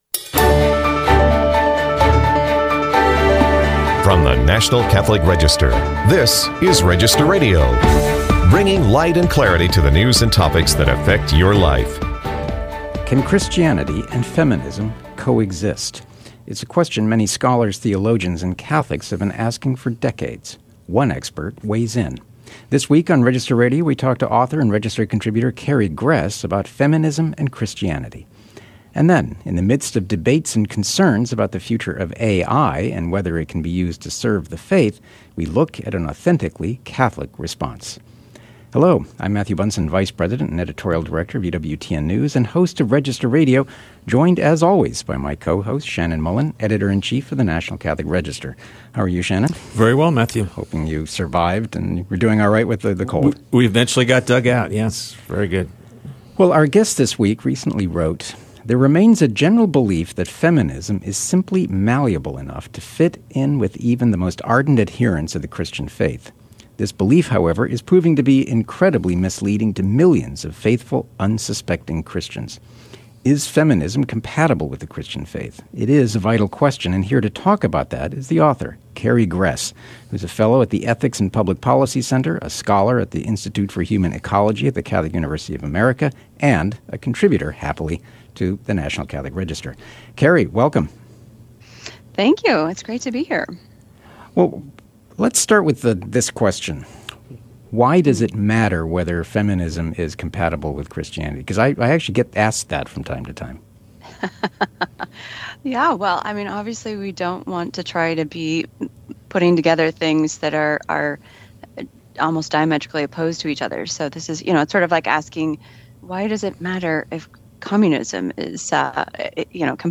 Episode from Register Radio